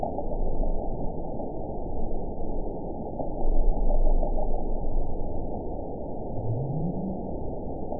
event 917171 date 03/23/23 time 01:00:05 GMT (2 years, 1 month ago) score 9.67 location TSS-AB03 detected by nrw target species NRW annotations +NRW Spectrogram: Frequency (kHz) vs. Time (s) audio not available .wav